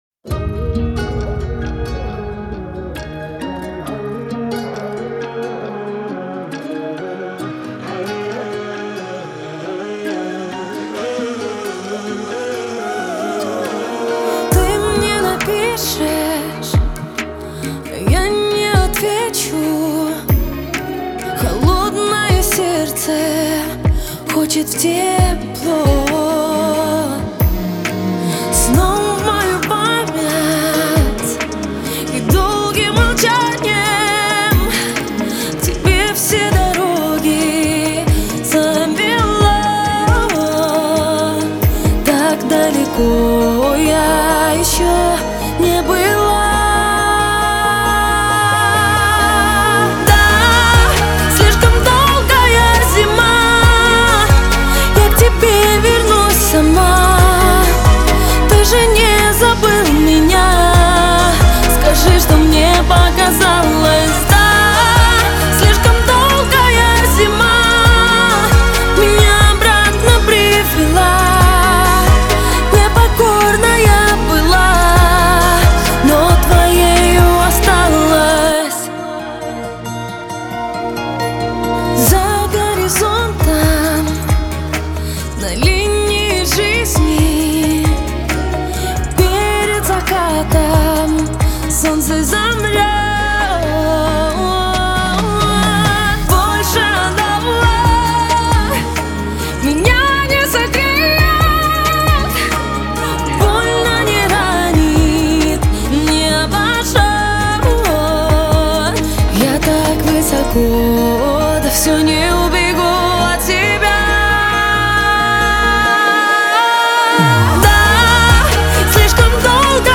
в жанре поп